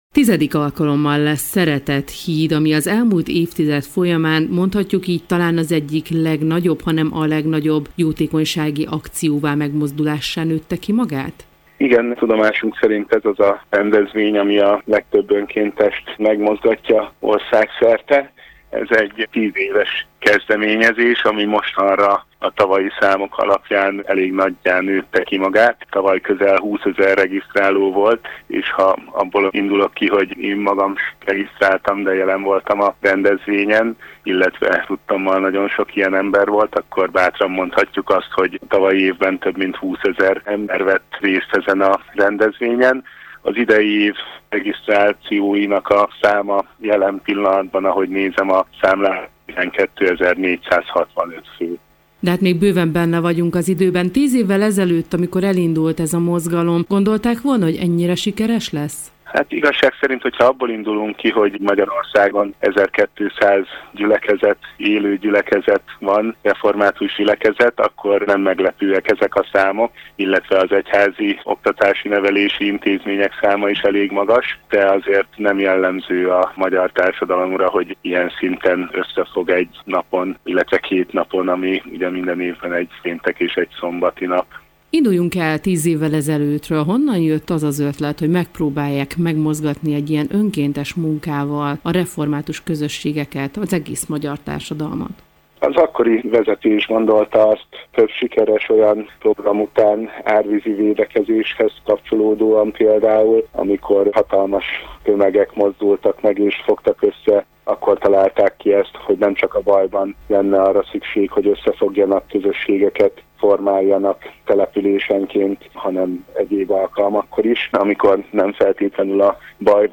Letöltés Forrás: Európa Rádió